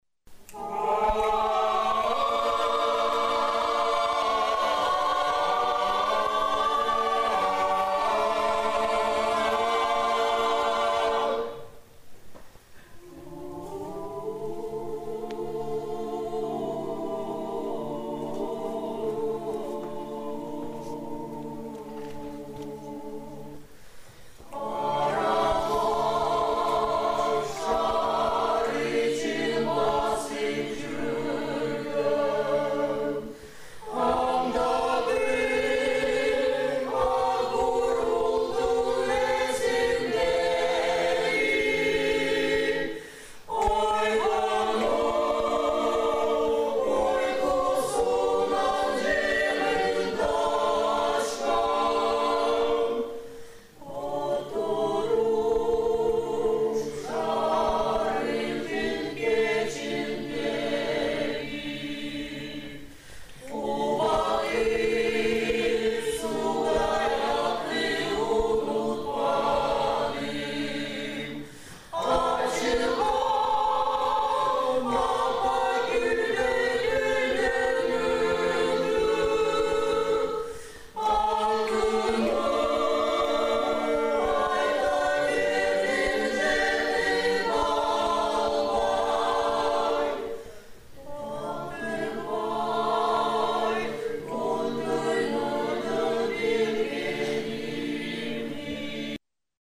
Хор-2